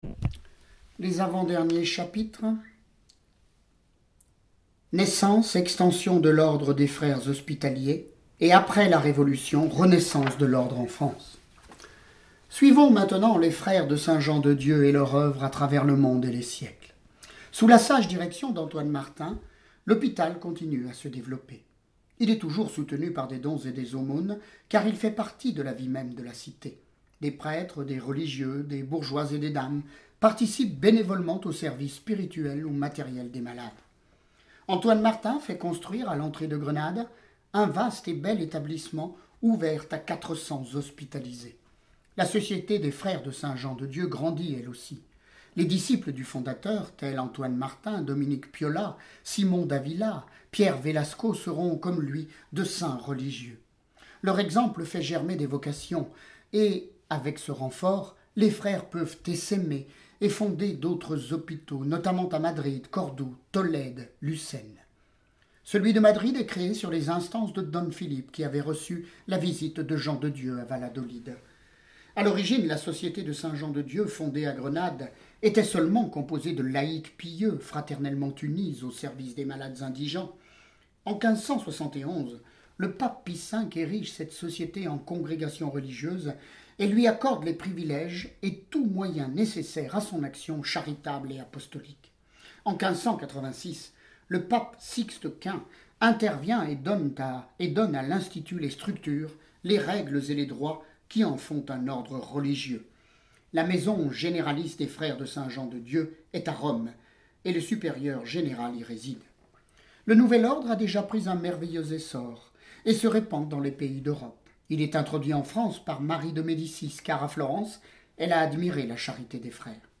Lecture de vies de Saints et Saintes